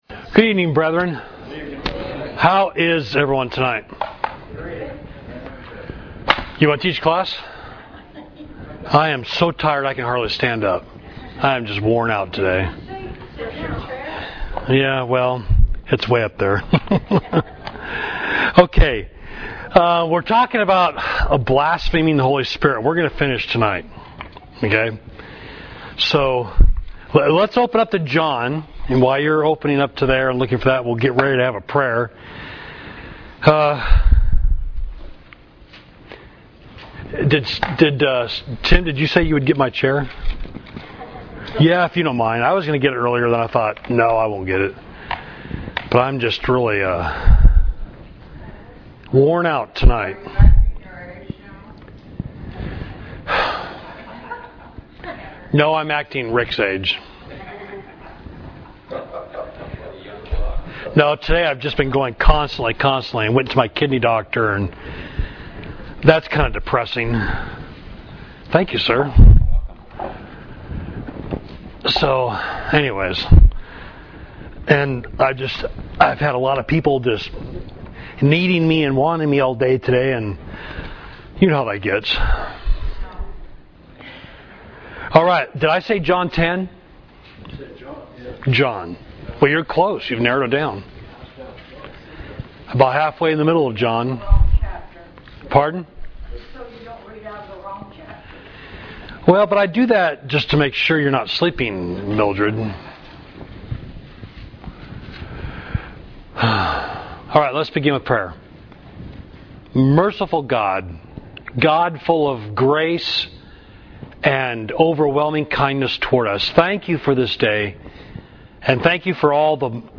Class: Blaspheming the Holy Spirit